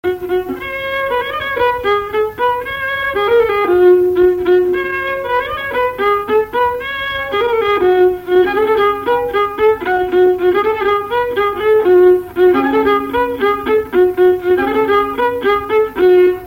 Mémoires et Patrimoines vivants - RaddO est une base de données d'archives iconographiques et sonores.
Couplets à danser
branle : courante, maraîchine
Pièce musicale inédite